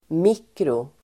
Uttal: [²m'ik:ro-]